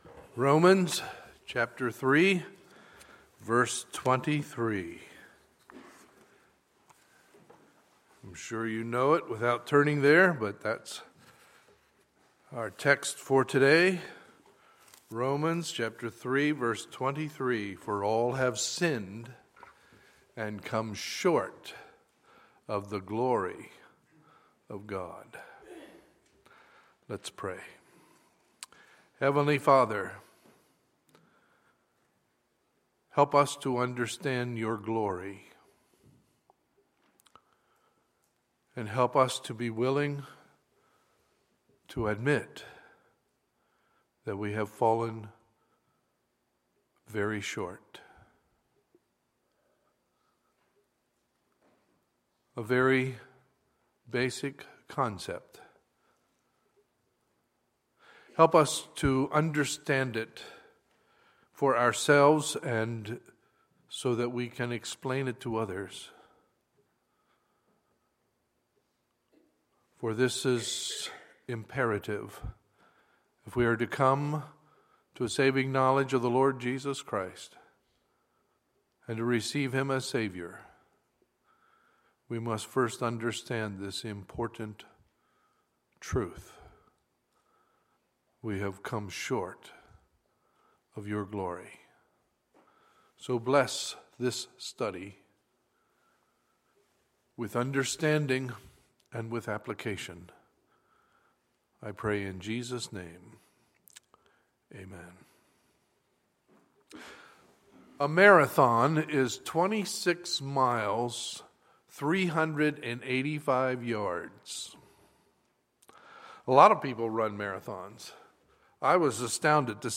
Sunday, February 21, 2016 – Sunday Morning Service
Sermons